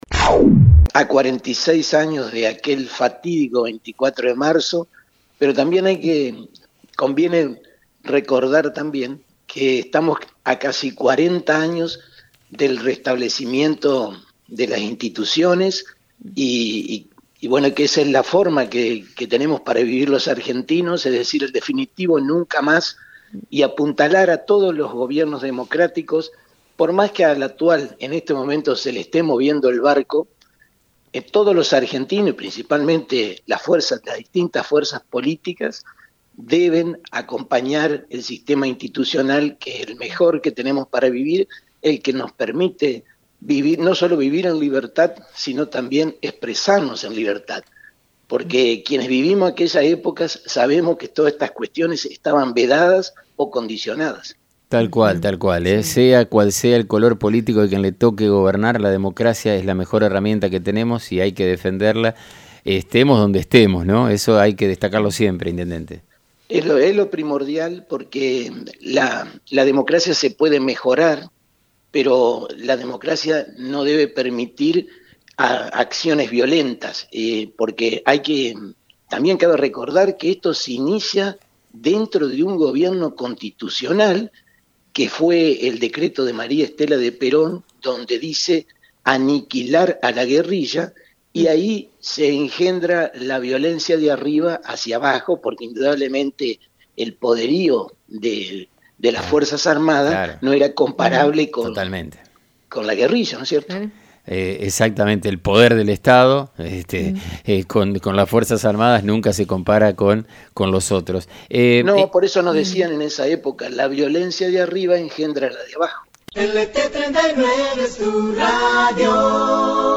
En el día de la Memoria, el intendente Domingo Maiocco dialogó con FM 90.3 y reflexionó sobre el significado del 24 de marzo.